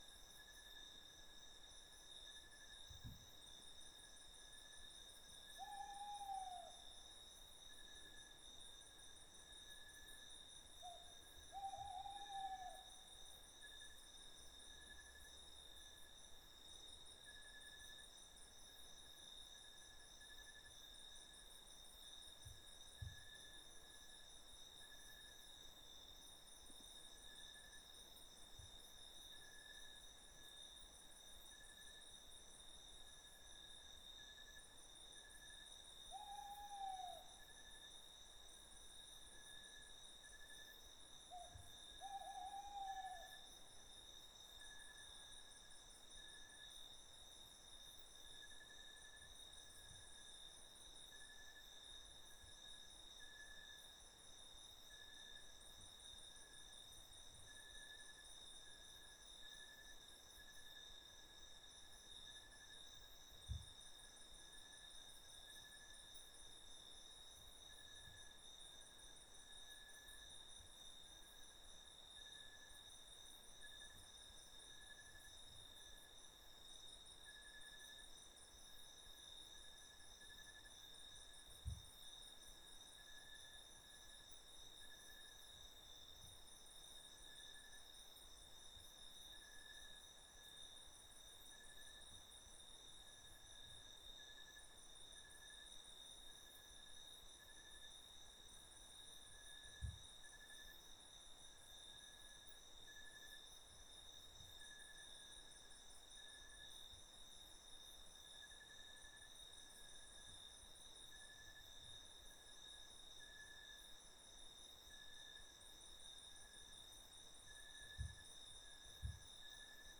MainForest-Night.ogg